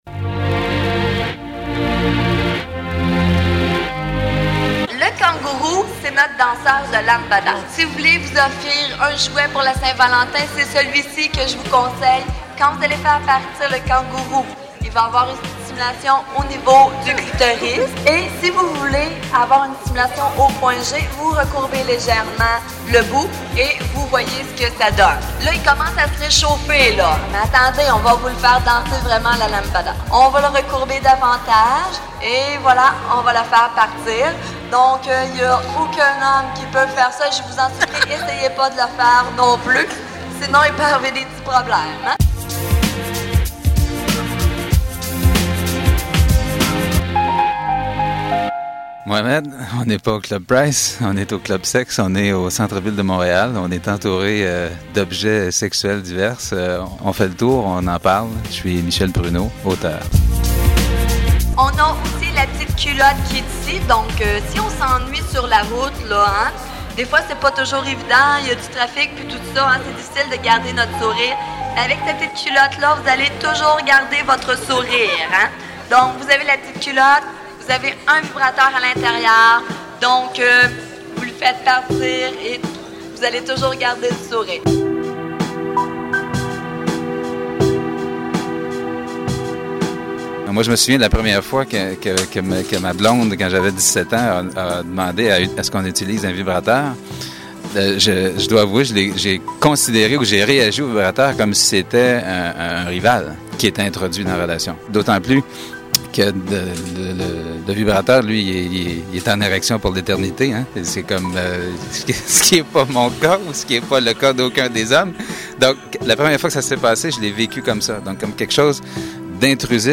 Reportage 9.59'